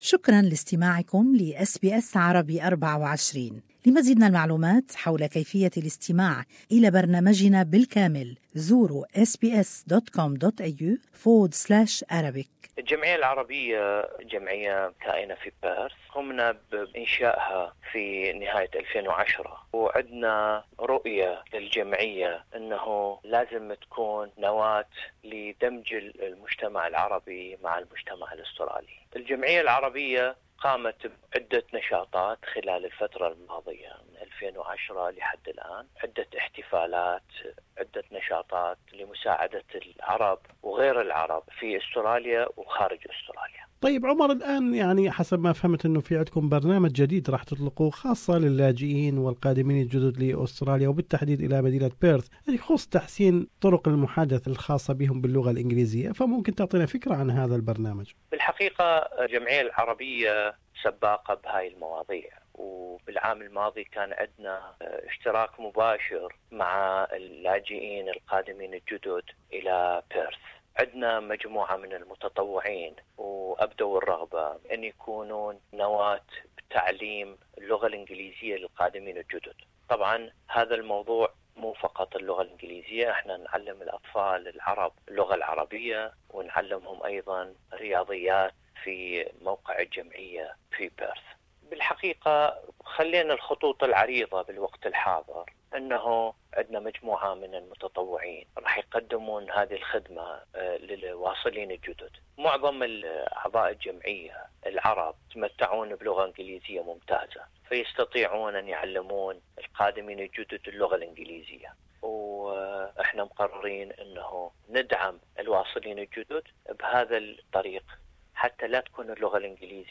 Australian Arab Association (AAA) is to launch a new program on enhancing new refugees skills in English. More is in this interview